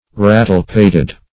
Search Result for " rattle-pated" : The Collaborative International Dictionary of English v.0.48: Rattle-pated \Rat"tle-pat`ed\, a. Rattle-headed.